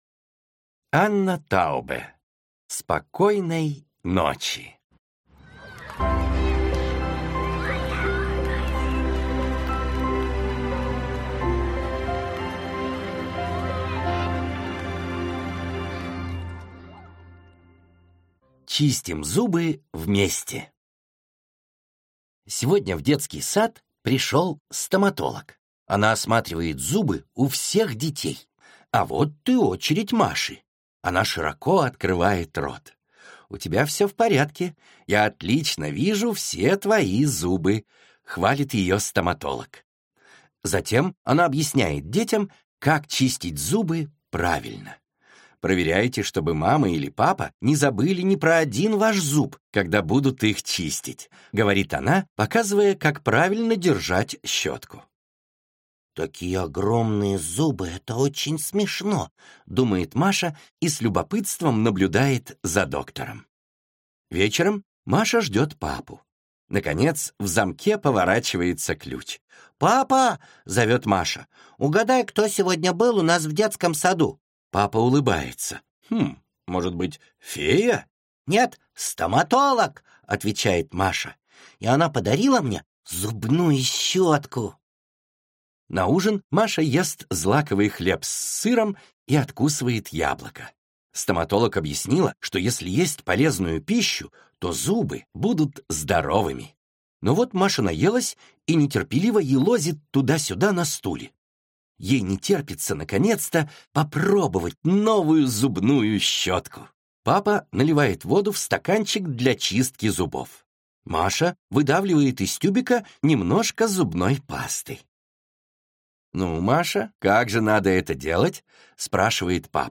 Аудиокнига Спокойной ночи!